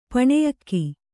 ♪ paṇeyakki